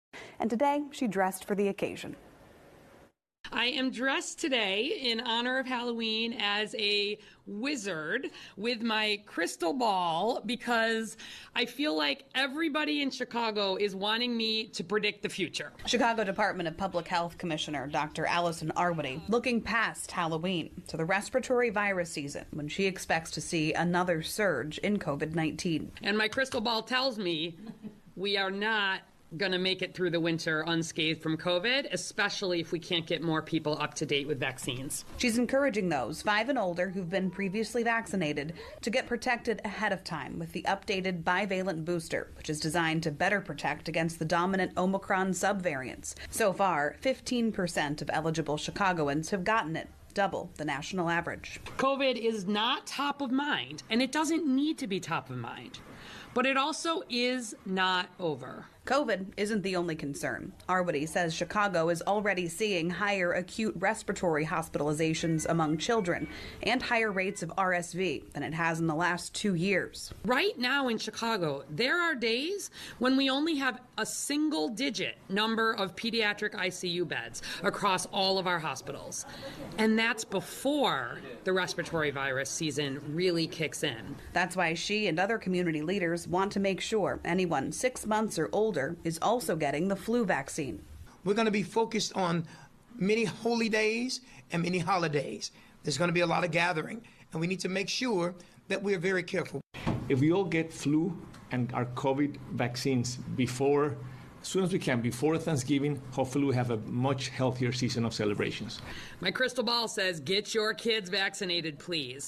Dr. Allison Arwady vom Chicago Gesundheitsamt verkleidet sich anlässlich Halloween als Hexe für die Pressekonferenz.